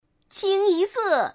Index of /client/common_mahjong_tianjin/mahjongwuqing/update/1161/res/sfx/changsha/woman/